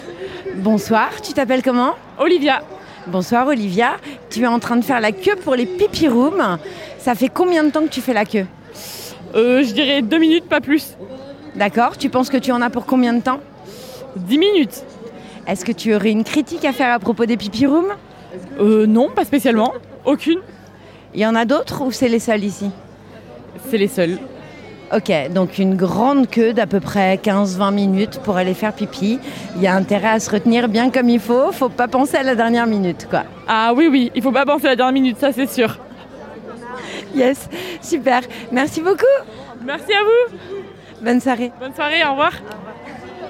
Interviews Festi'malemort